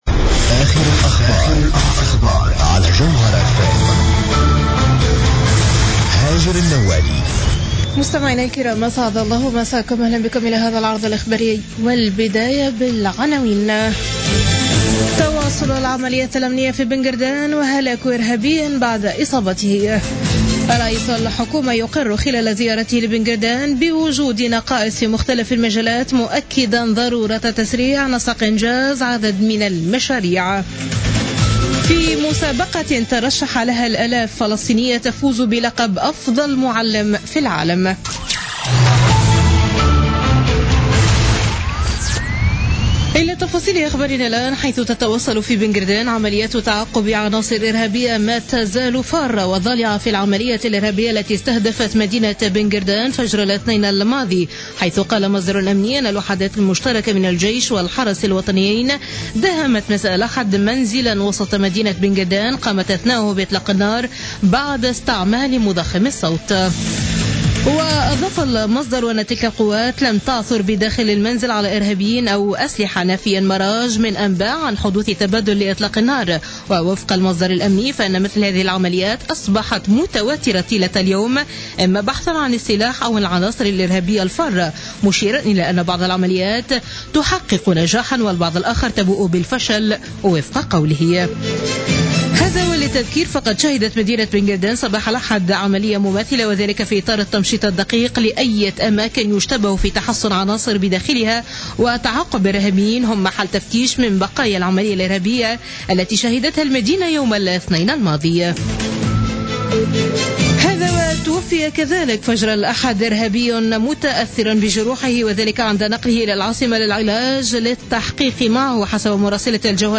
نشرة أخبار منتصف الليل ليوم الاثنين 14 مارس 2016